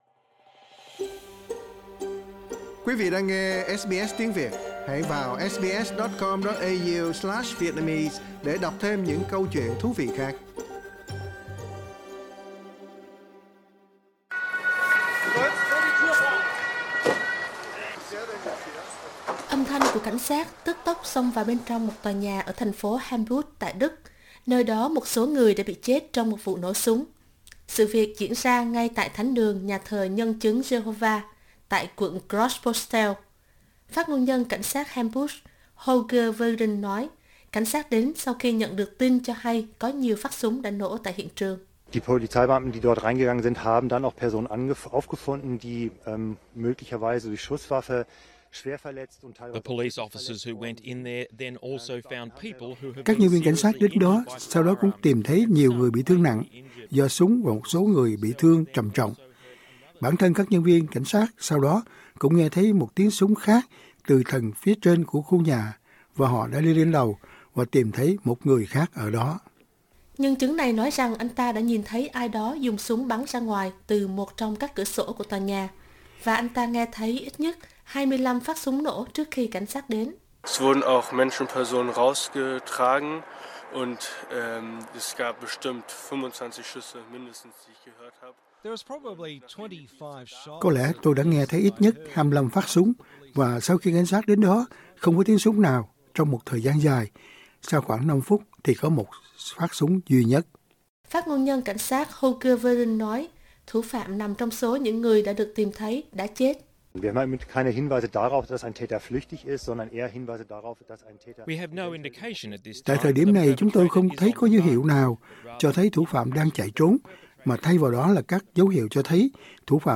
Âm thanh của cảnh sát tức tốc xông vào bên trong một tòa nhà ở thành phố Hamburg tại Đức, nơi đó một số người đã bị chết trong một vụ nổ súng.